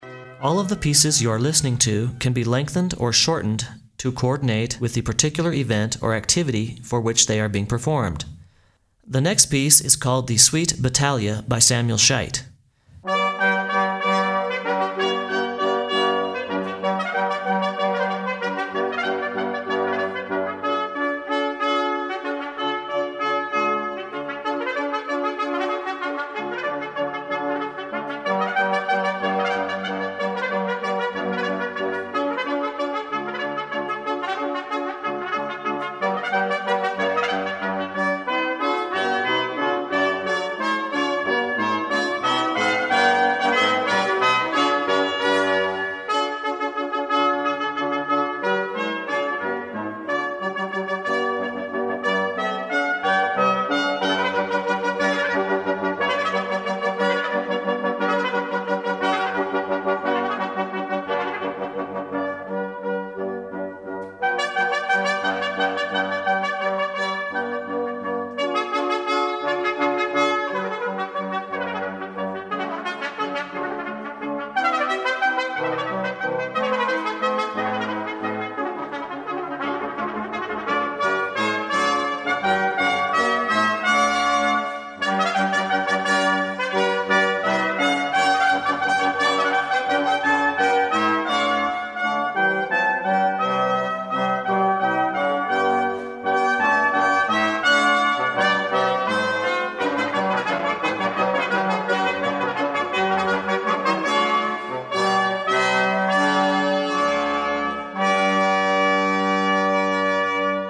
Denver brass quintet
The Peak Brass Quintet performs a wide repertoire of classical music.